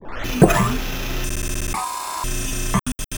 program_start.wav